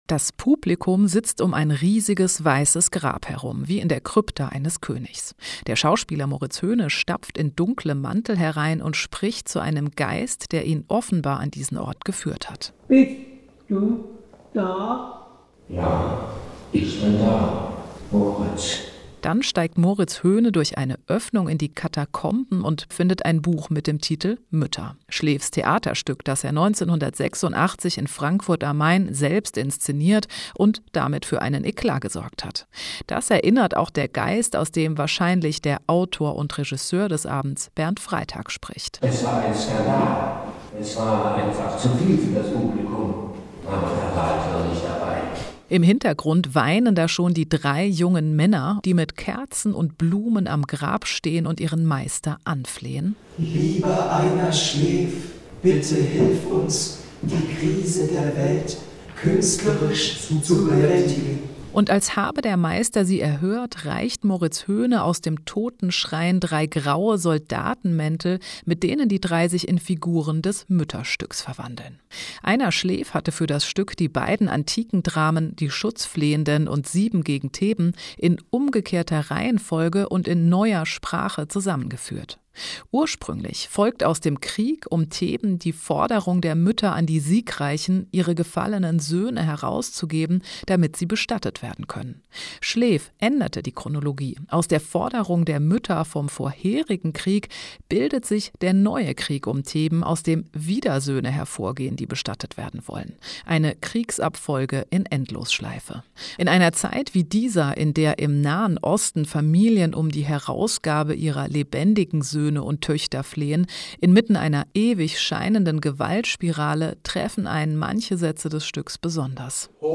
Premierenkritik - "Schleef, ein Vorspiel" vom RambaZamba Theater: "Kryptische Hommage"